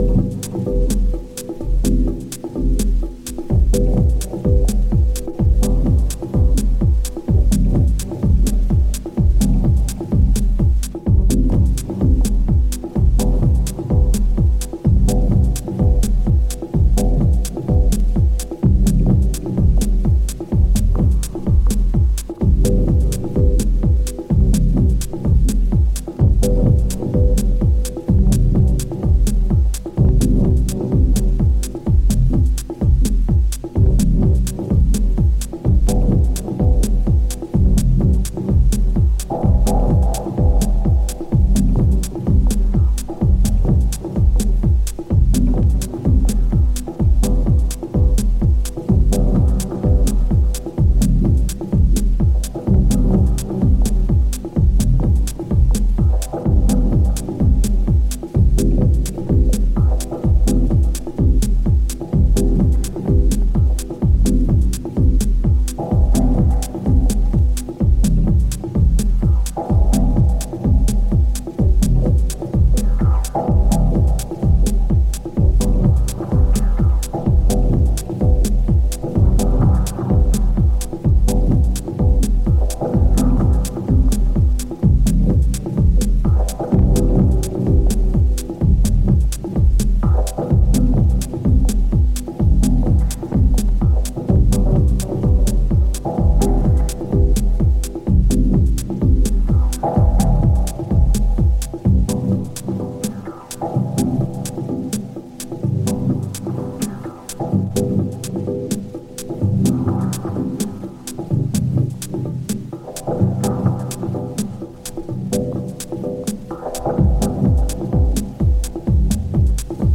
New Release Dub Techno Techno